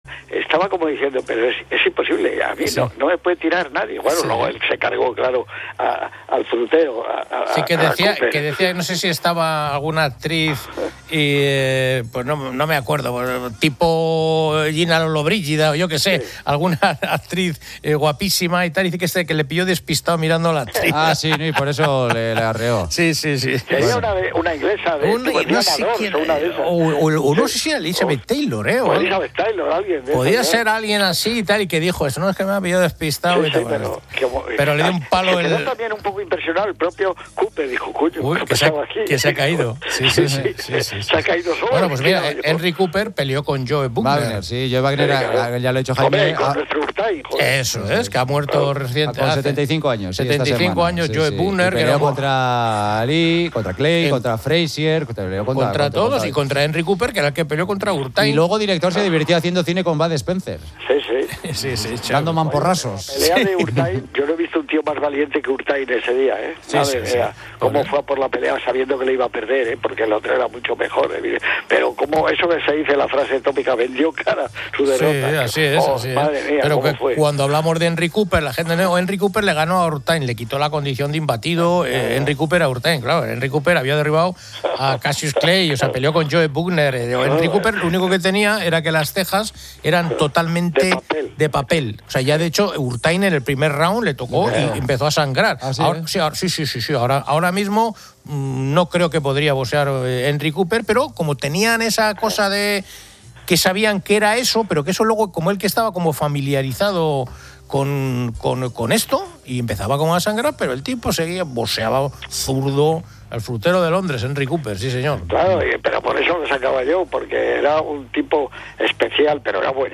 La conversación deriva hacia el boxeo de exhibición, como el anunciado combate Floyd Mayweather vs. Mike Tyson, y el fenómeno Jake Paul, enfatizando el aspecto de negocio y entretenimiento. También se bromea sobre Conor McGregor presentándose a la presidencia de Irlanda.